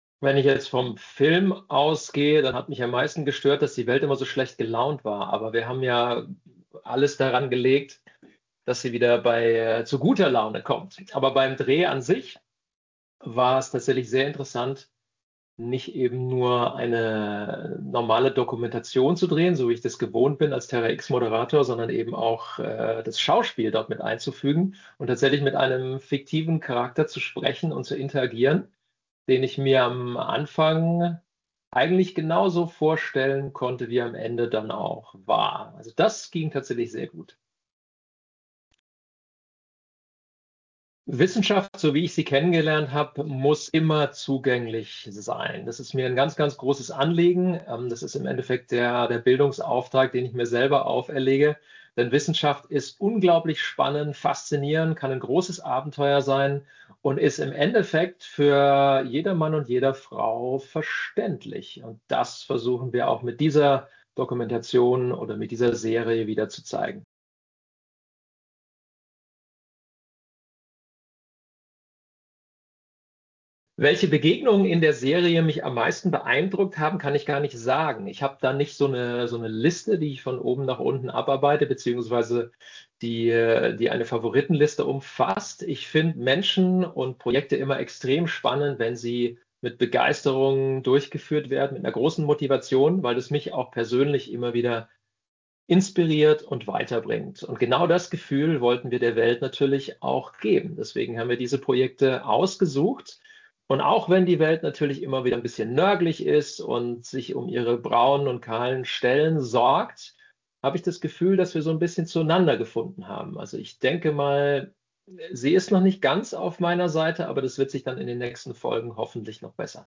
Verfügbare Audio-O-Töne: